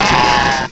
cry_not_cranidos.aif